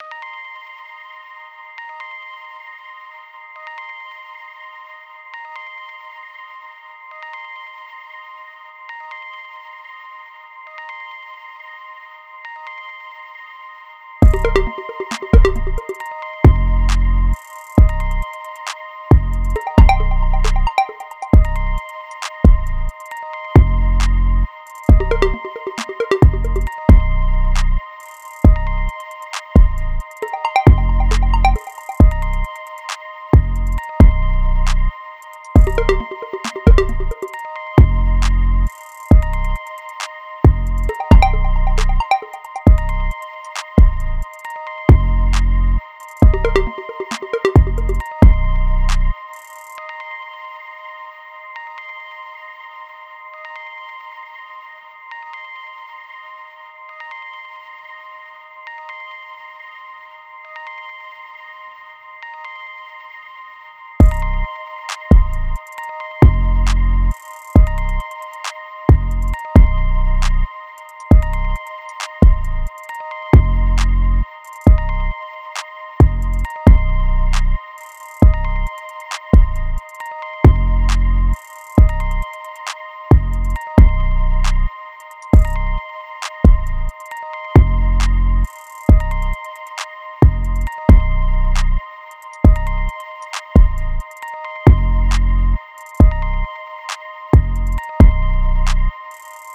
Time – (1:40)　bpm.135